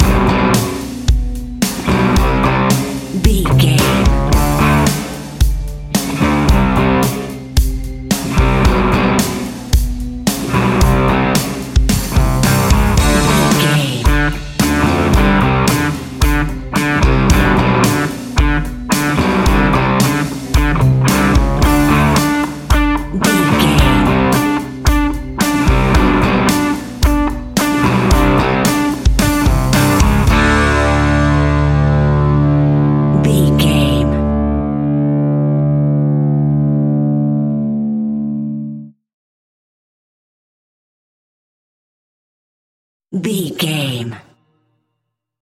Ionian/Major
D
energetic
driving
aggressive
electric guitar
bass guitar
drums
hard rock
heavy metal
distortion
distorted guitars
hammond organ